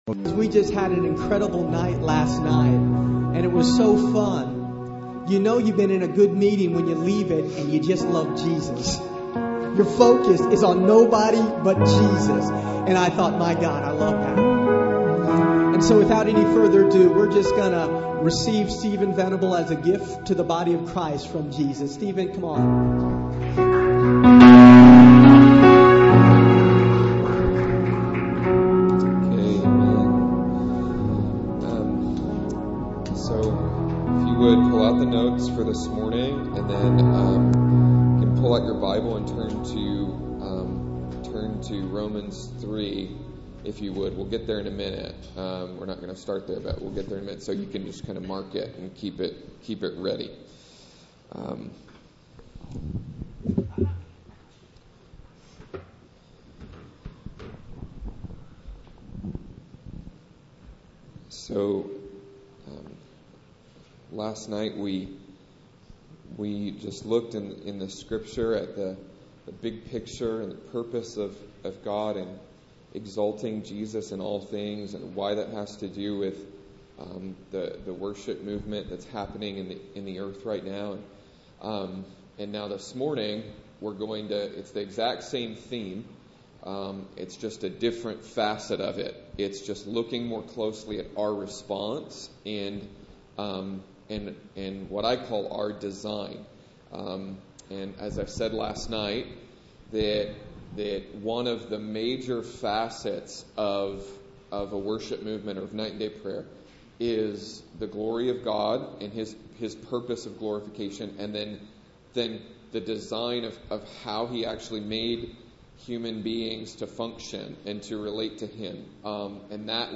Scripture reveals that God designed man in a priestly capacity and Jesus saved man from his sin to restore that created purpose. In this second message from the Key of David Prayer Conference held at Ethnos Missions Center